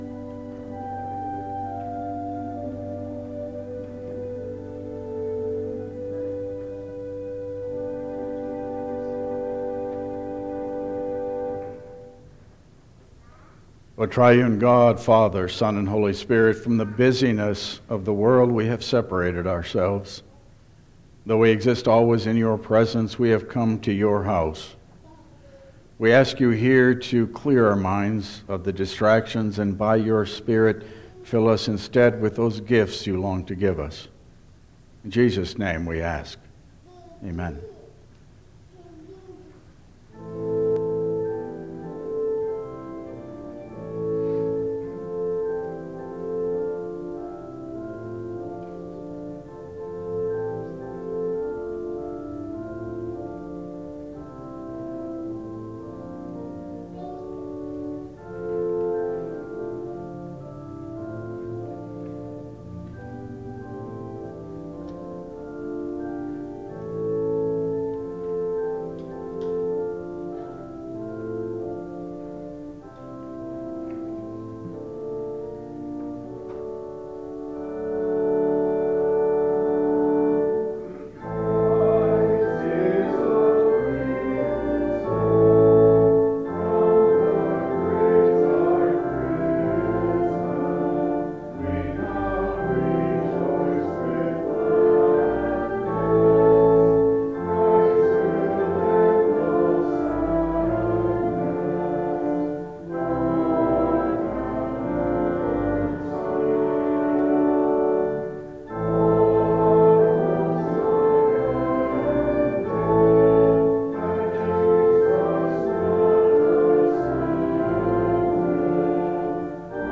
Service Type: Regular Service